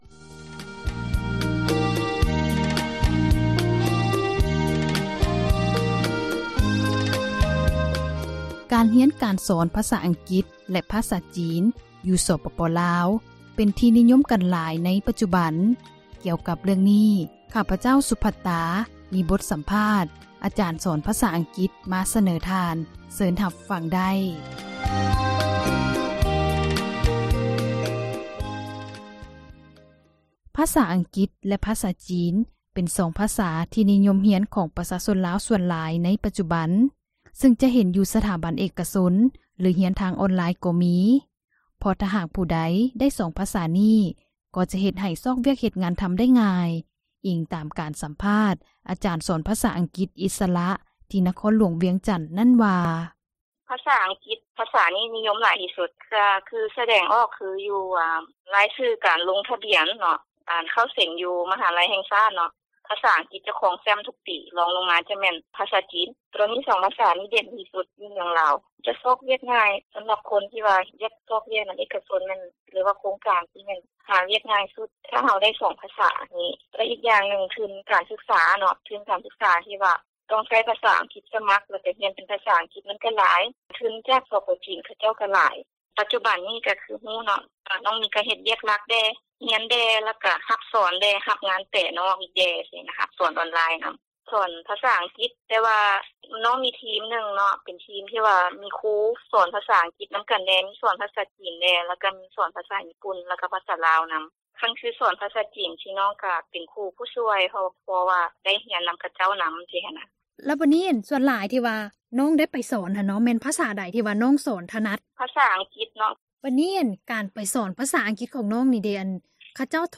ສັມພາດຄຣູສອນພາສາ ອັງກິດ